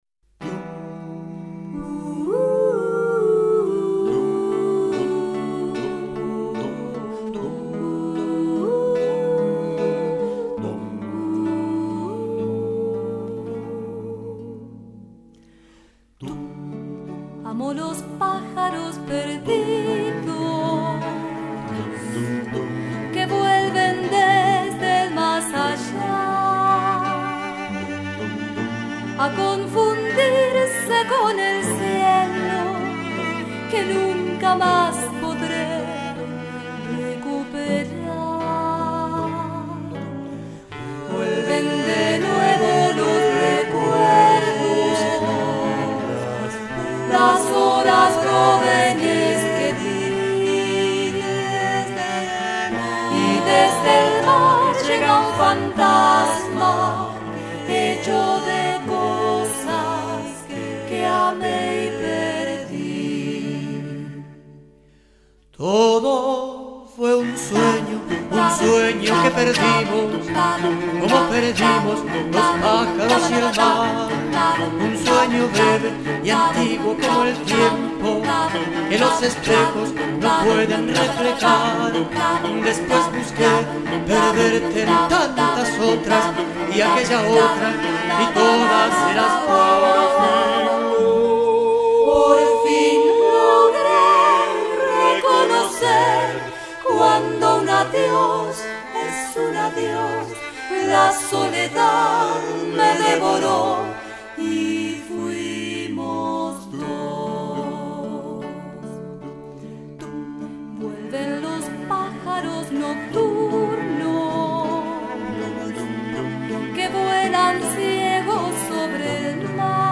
Partituras Corales